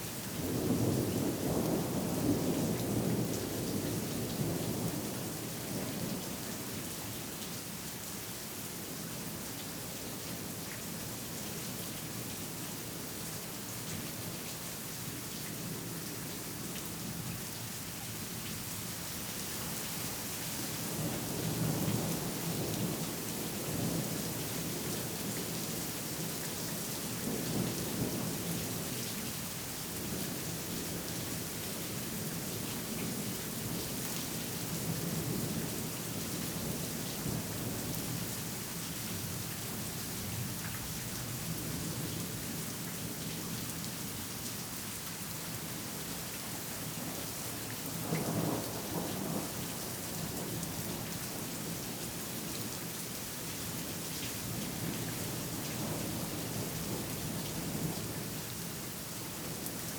Weather Evening Medium Rain Thunder Rustling Trees ST450 02_ambiX.wav